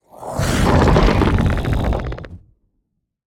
Minecraft Version Minecraft Version snapshot Latest Release | Latest Snapshot snapshot / assets / minecraft / sounds / mob / warden / roar_2.ogg Compare With Compare With Latest Release | Latest Snapshot
roar_2.ogg